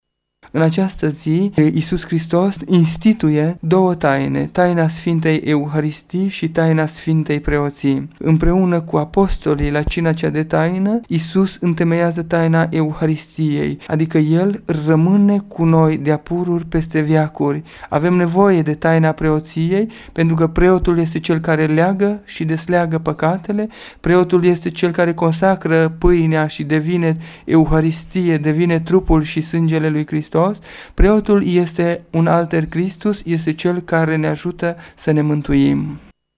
ne spune preotul greco-catolic din Tg Mureş